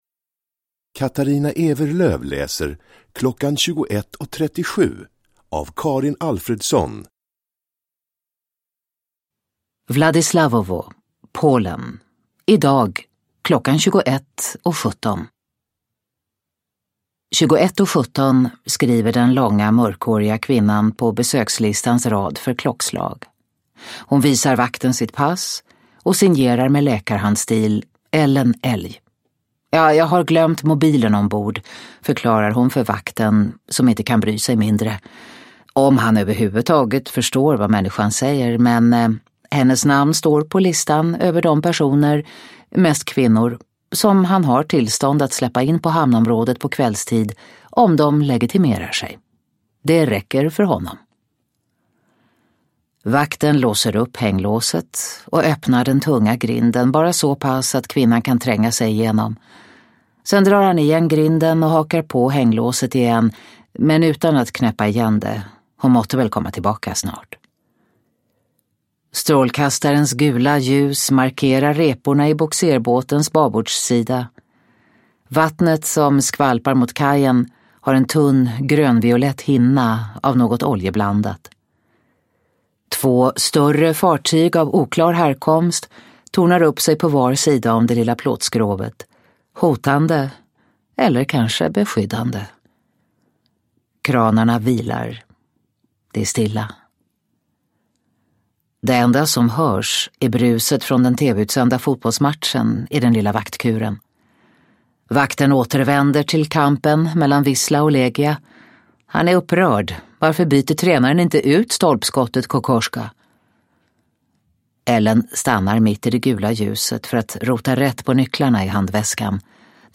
Klockan 21.37 – Ljudbok – Laddas ner
Uppläsare: Katarina Ewerlöf